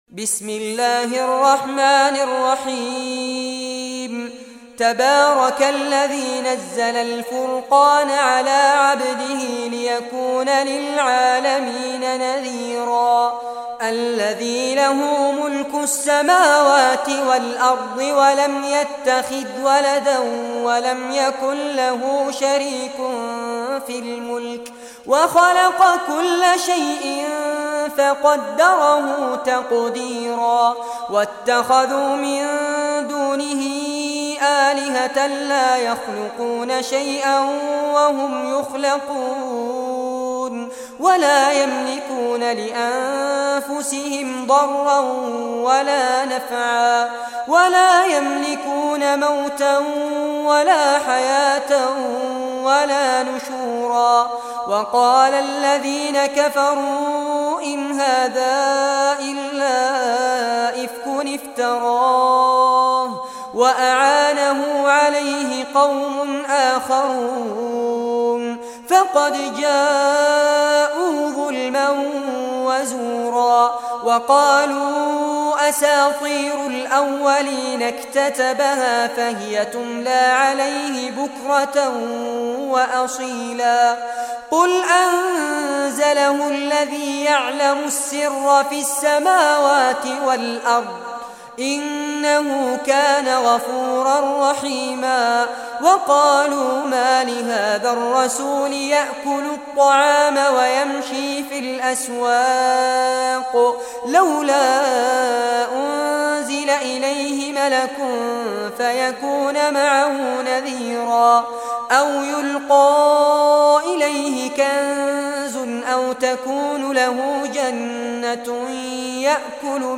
Surah Al-Furqan Recitation by Fares Abbad
Surah Al-Furqan, listen or play online mp3 tilaawat / recitation in Arabic in the beautiful voice of Sheikh Fares Abbad Download audio tilawat of Surah Al-Furqan free mp3 in best audio quality.
25-surah-furqan.mp3